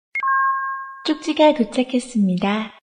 msg_recv_alarm.mp3